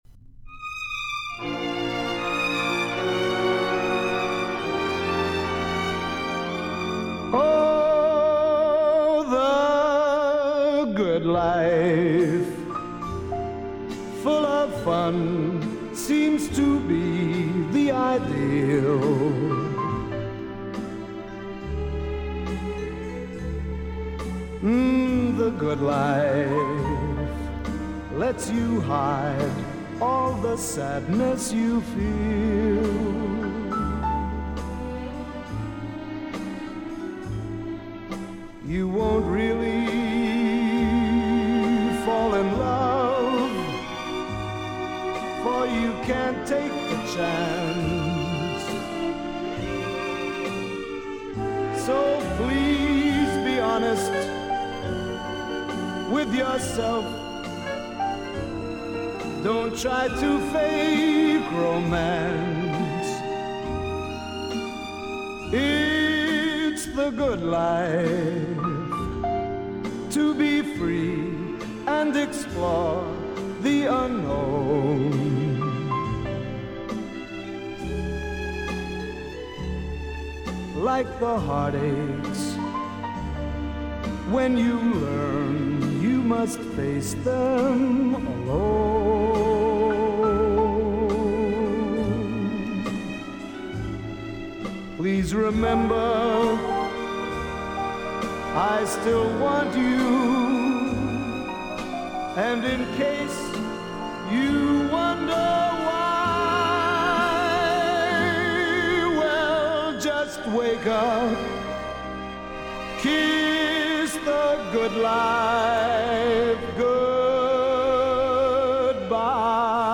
Жанры Свинг
Поп-музыка
Джаз